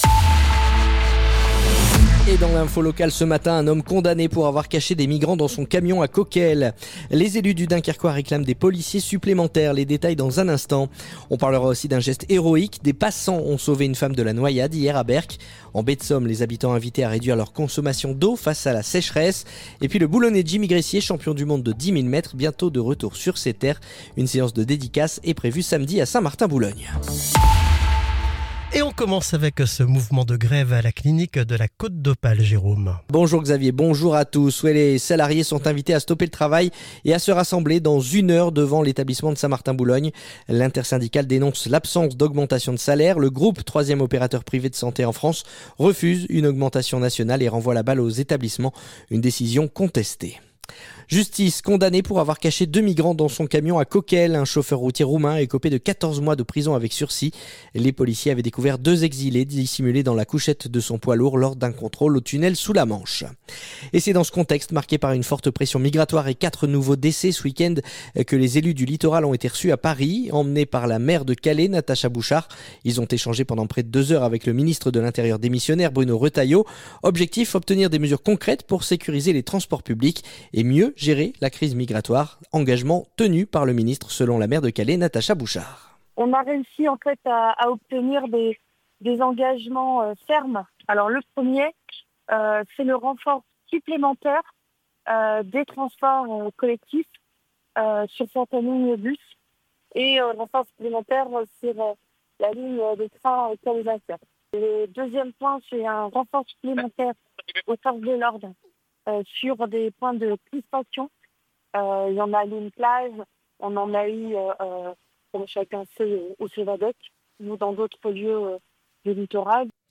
Le journal du mardi 30 septembre